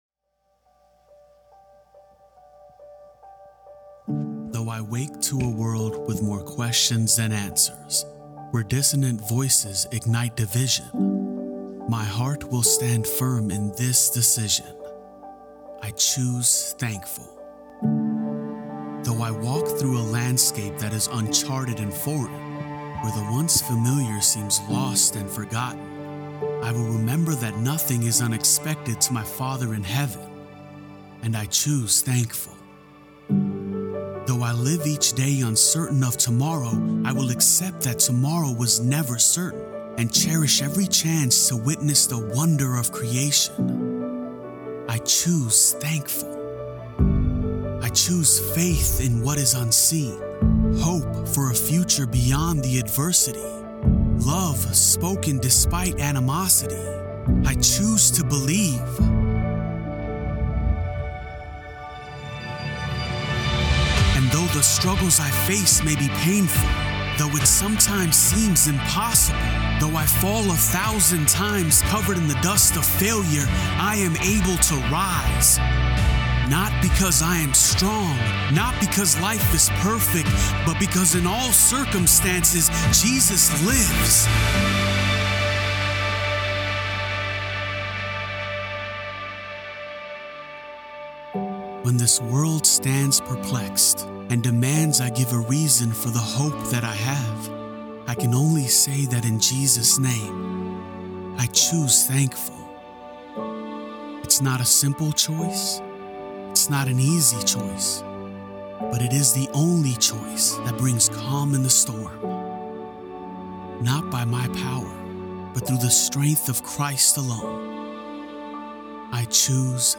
Choosing Gratitude (Week 1) - Sermon.mp3